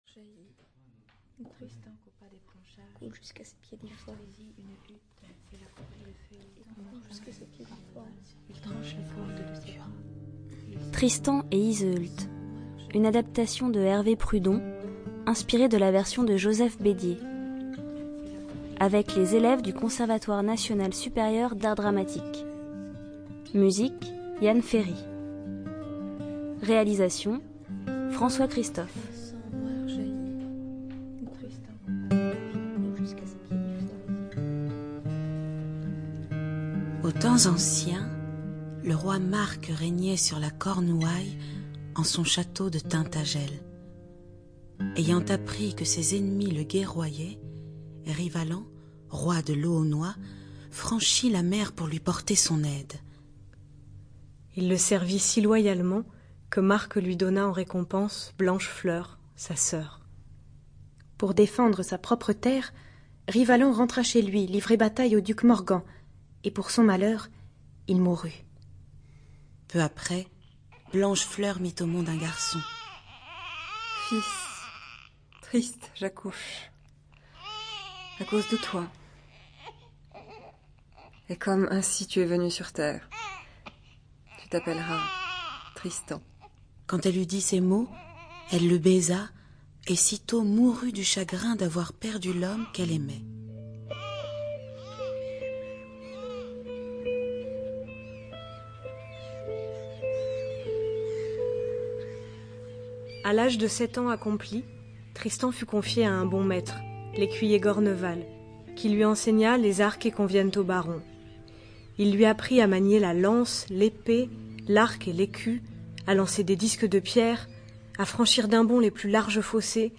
ЖанрРадиоспектакли на языках народов Мира